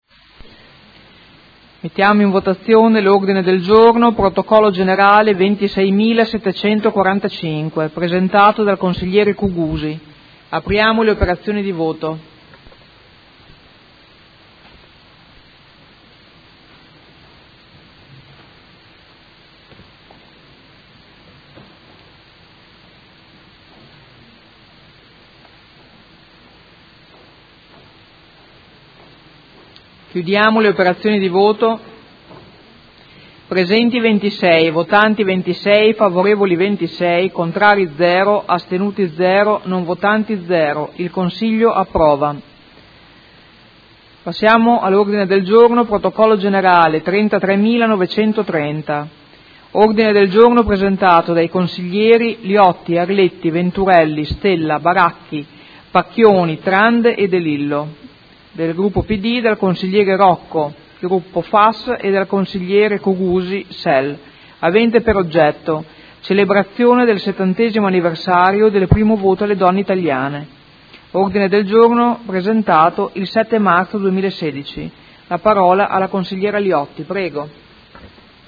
Seduta del 31/03/2016. Ordine del Giorno presentato dal Consigliere Cugusi (SEL) avente per oggetto: Solidarietà agli amministratori pubblici e al Procuratore Gratteri minacciati dalle mafie.